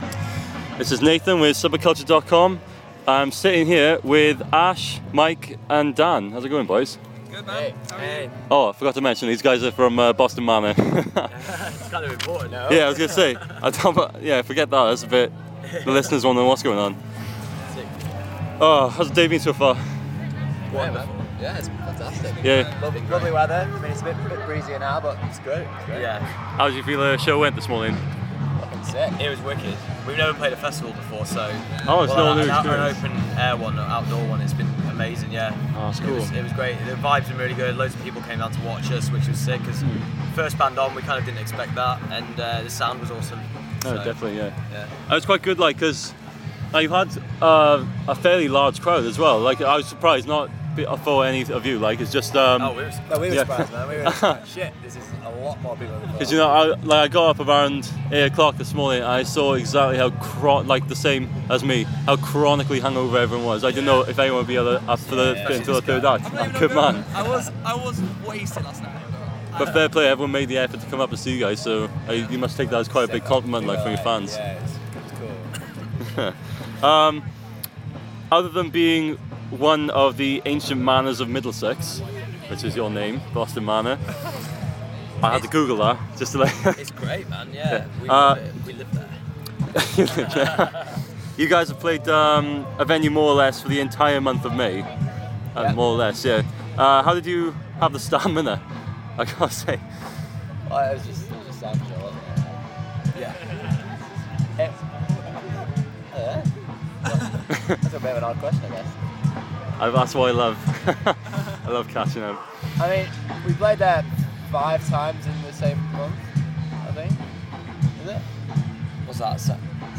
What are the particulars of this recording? Boston Manor's interview with Subba-Cultcha at 2000 trees festival 2015